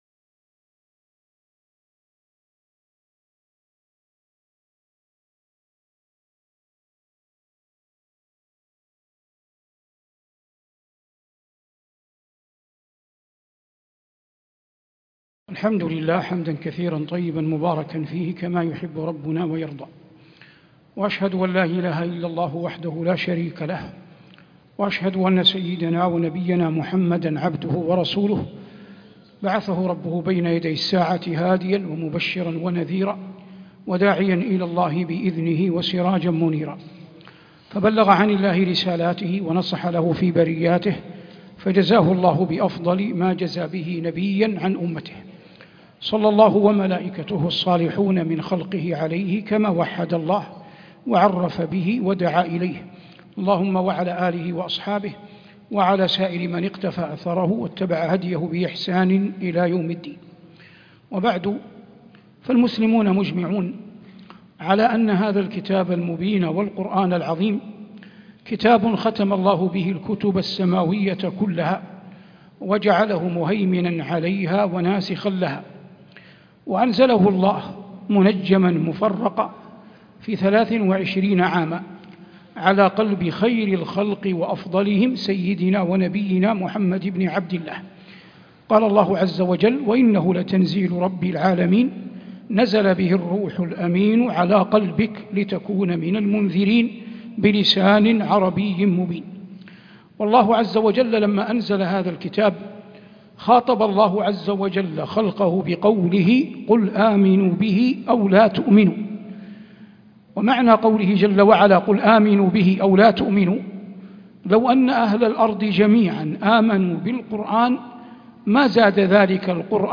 محاضرة أثر القرآن في حياة المسلم - الشيخ صالح المغامسي - مسجد عائشة بحي المسرَّة بجدة - الشيخ صالح بن عواد المغامسى